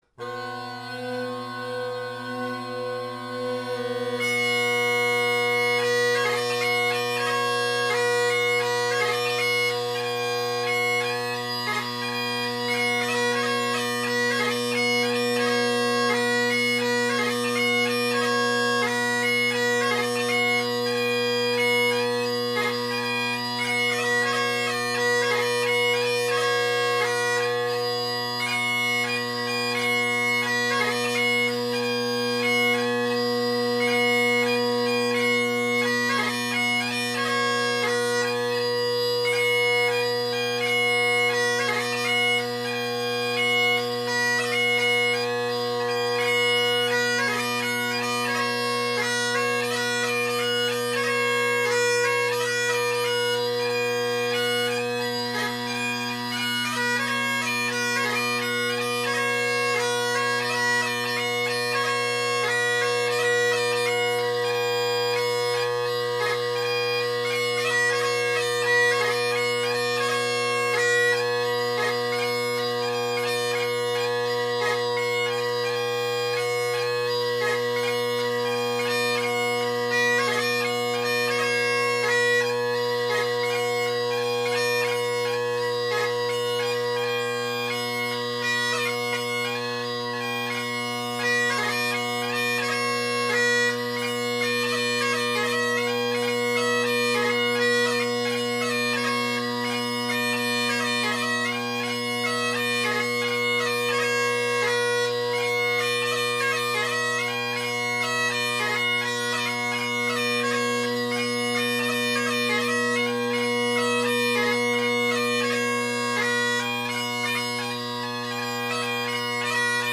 Drone Sounds of the GHB, Great Highland Bagpipe Solo
I hope you enjoy listening in on my jams: